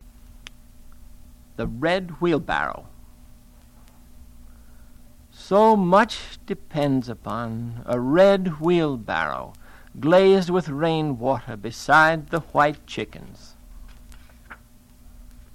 Figure 1. Sonification of William Carlos Williams’s “The Red Wheelbarrow,” first two stanzas, Library of Congress recording (1945).